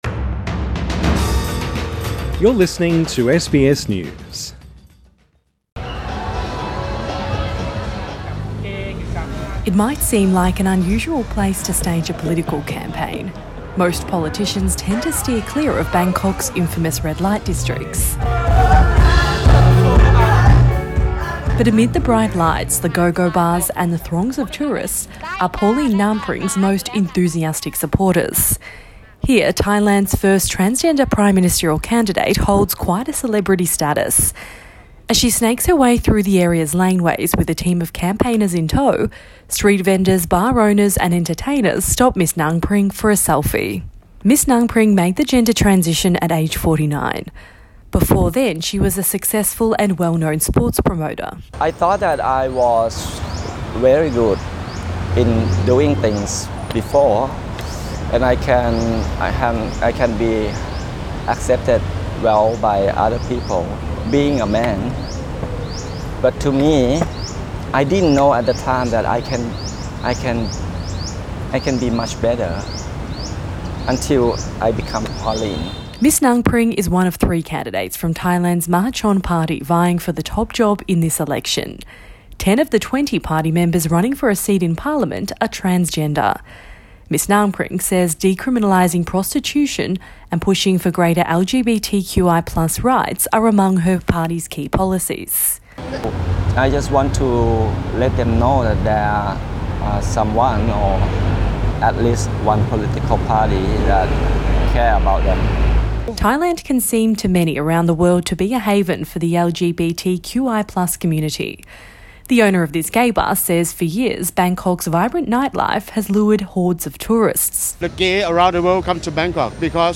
Thai prime ministerial candidate Pauline Ngarmpring is interviewed in Bangkok Source: SBS